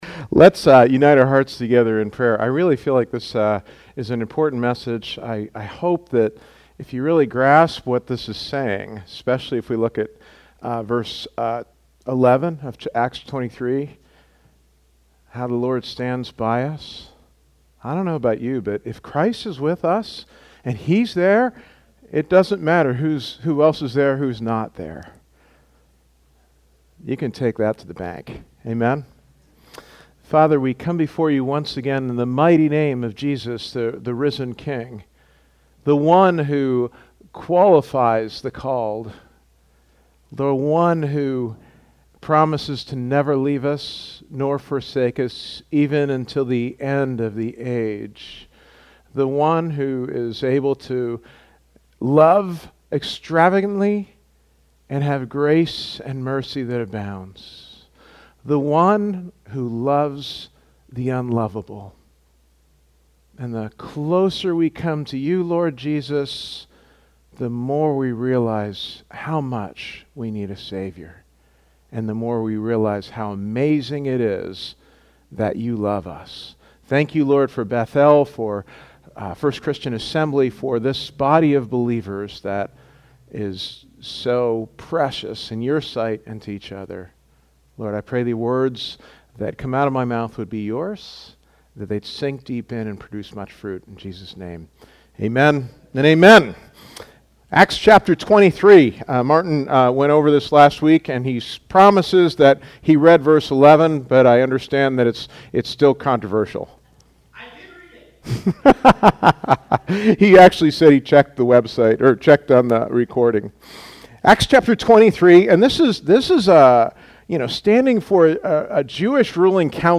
Sermons | Bethel Christian Church